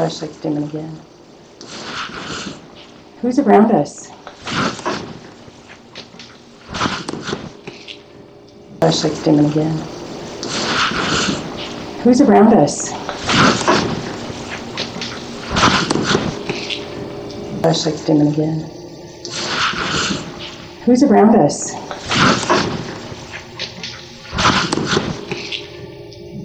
Basement